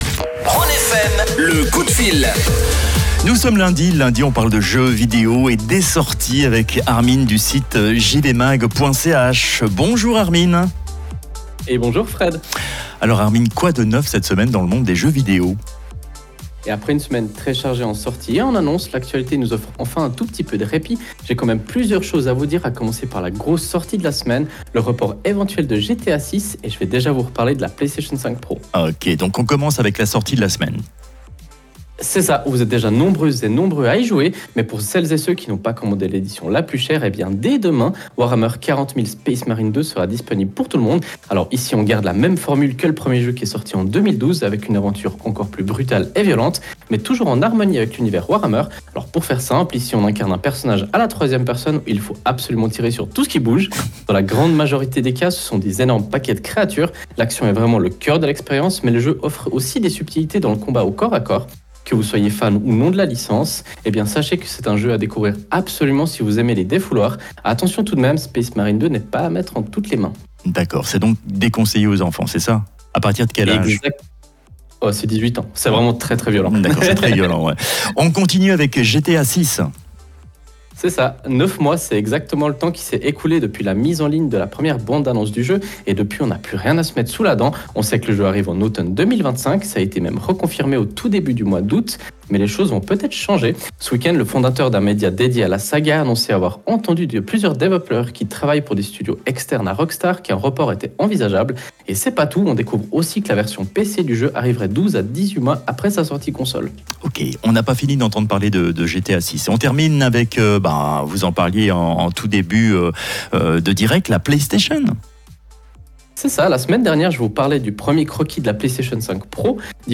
Comme tous les lundis, nous avons la chance de présenter notre chronique gaming sur la radio Rhône FM. Une capsule gaming qui retrace l’actualité du moment.
Vous pouvez réécouter le direct Rhône FM via le flux qui se trouve juste en haut de l’article.